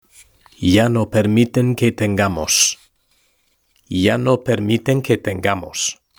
Lectura en voz alta: 3.2 Los medios de comunicación y la tecnología (H)